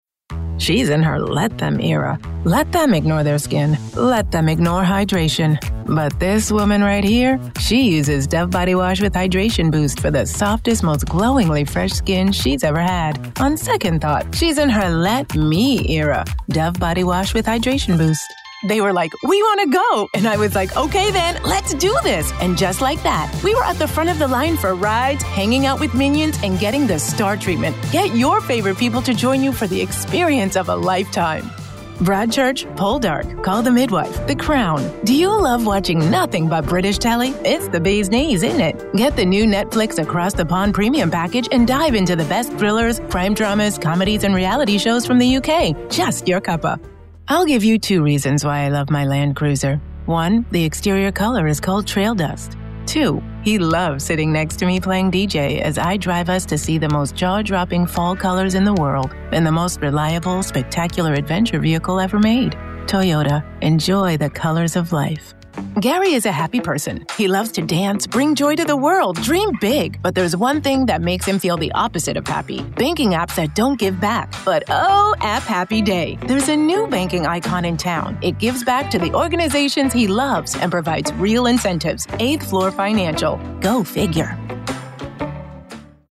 Serious, passionate political advocate. Upbeat, friendly retail. Cool, unpretentious, upscale. Friendly young mom next door.
African American, animated, announcer, anti-announcer, attitude, caring, confident, conversational, cool, friendly, genuine, inspirational, mother, real, retail, serious, Straight Forward, thoughtful, tough, young adult